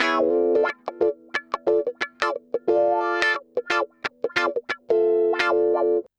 Index of /90_sSampleCDs/USB Soundscan vol.04 - Electric & Acoustic Guitar Loops [AKAI] 1CD/Partition C/05-089GROWAH